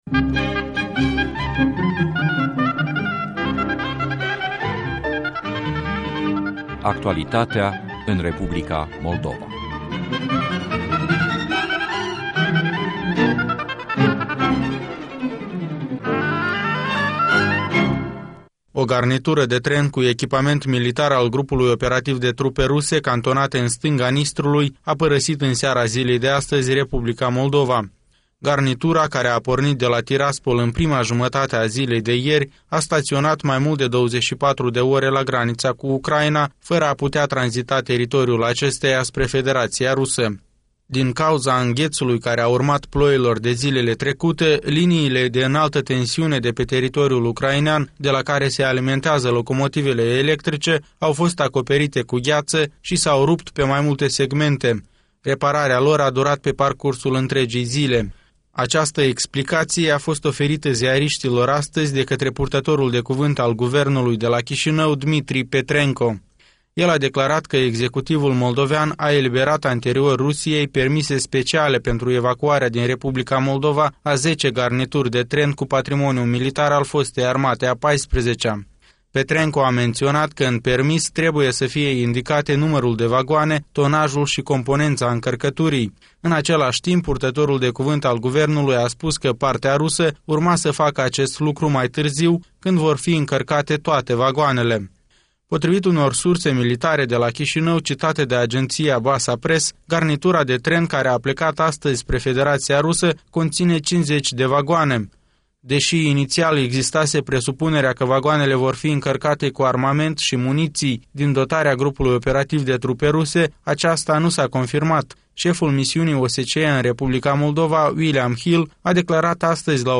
Conferința de presă a lui William Hill